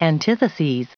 Prononciation du mot antitheses en anglais (fichier audio)
Prononciation du mot : antitheses